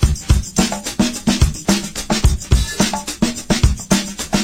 • 108 Bpm Drum Loop C Key.wav
Free drum groove - kick tuned to the C note.
108-bpm-drum-loop-c-key-hHU.wav